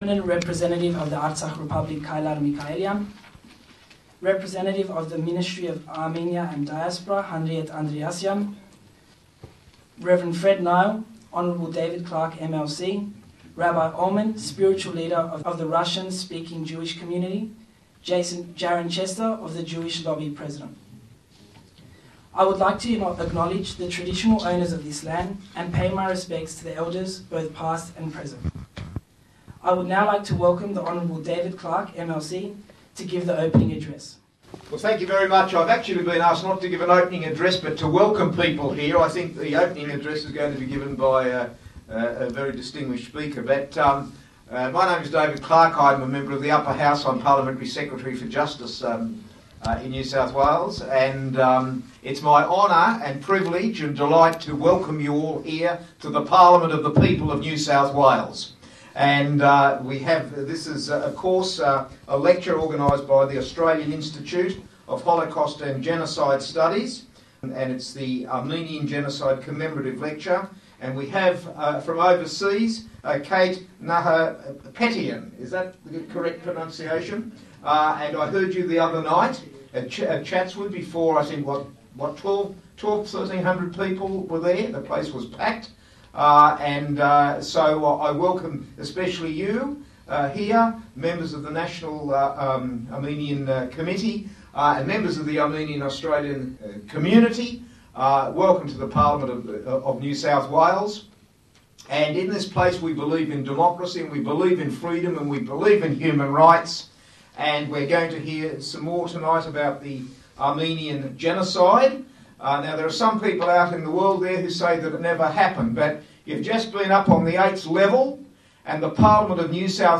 2017 Armenian Genocide Commemorative Lecture
The 2017 Armenian Genocide Commemorative Lecture in Sydney was held on May 1 in the NSW Parliament.